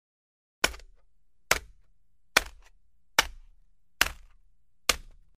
Звуки молотка судьи
На этой странице собраны звуки молотка судьи — от четких одиночных ударов до протяжных стуков.
Отбивают молотком